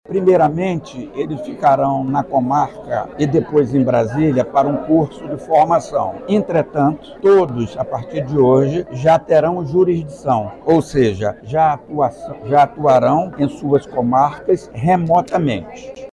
O evento foi realizado no Auditório do Centro Administrativo do TJAM, localizado na zona Centro-Sul de Manaus.
O desembargador destacou, ainda, que após a posse, os juízes e juízas vão passar por um treinamento, em Brasília, mas devem atuar em suas comarcas de forma remota.